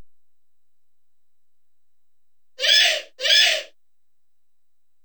Dinosaur King Tupuxuara Call
Category: Sound FX   Right: Personal